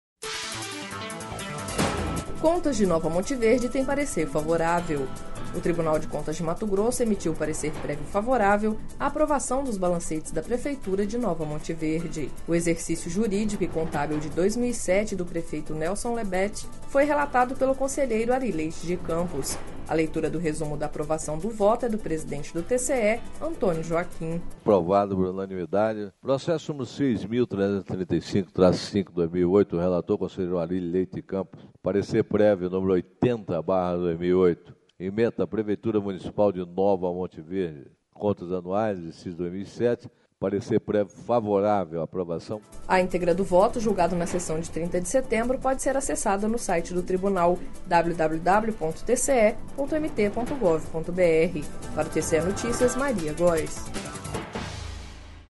Sonora: Antonio Joaquim – presidente do TCE-MT